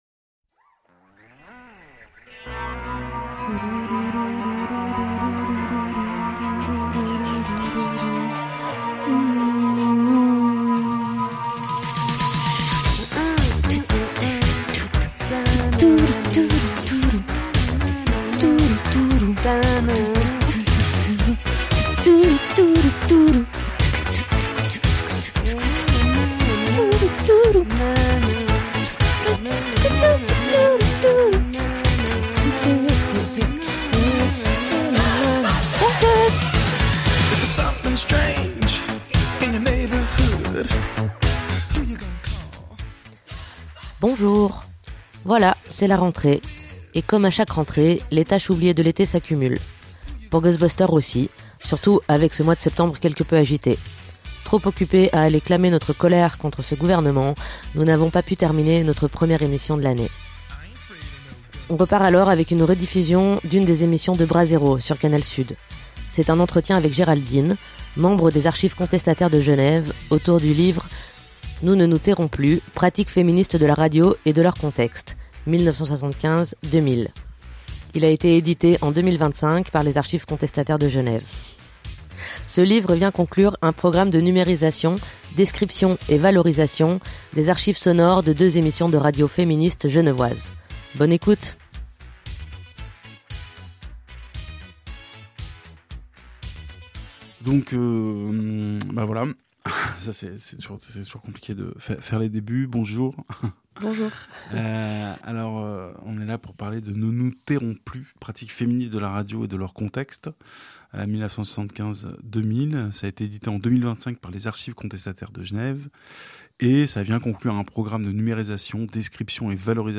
Pour cette émission de rentrée, voici un entretien